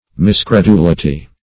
Search Result for " miscredulity" : The Collaborative International Dictionary of English v.0.48: Miscredulity \Mis`cre*du"li*ty\, n. Wrong credulity or belief; misbelief.
miscredulity.mp3